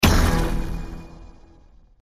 RGX 11z Pro Kill 1 sound effects